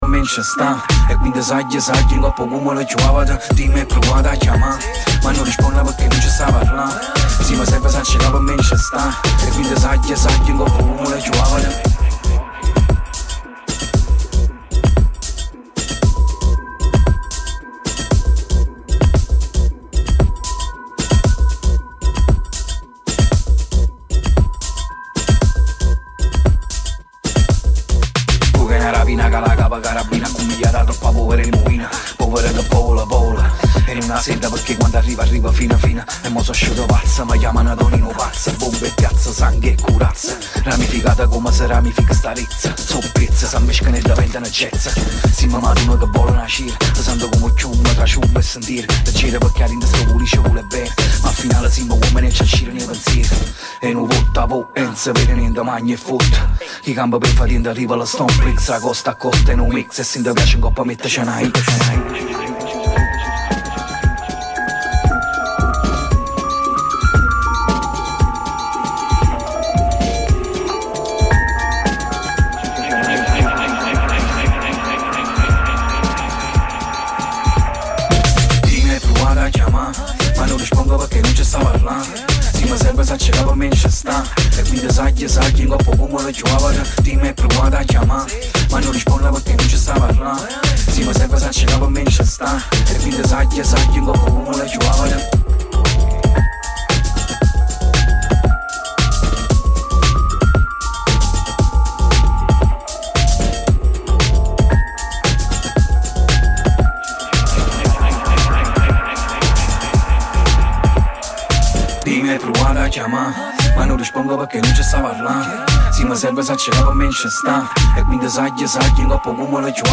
Puntata a base di Boom Bap e classiconi.